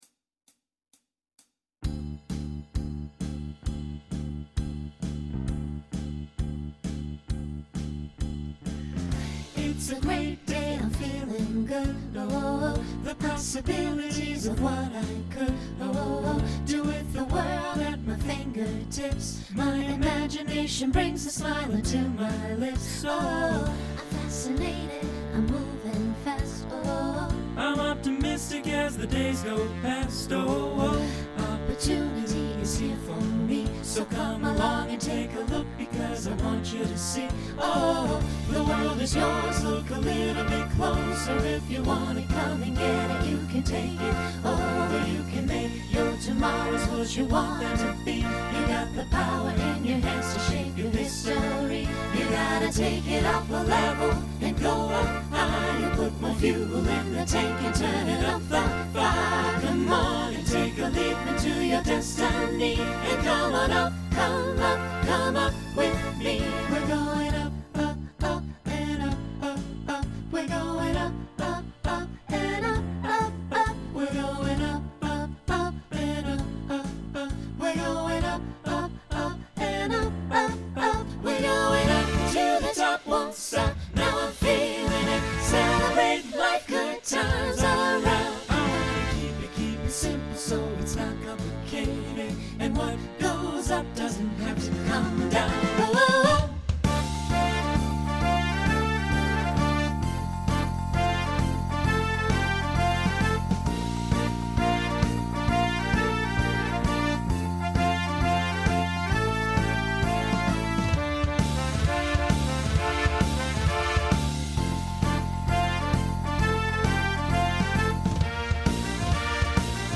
Genre Swing/Jazz Instrumental combo
Voicing SATB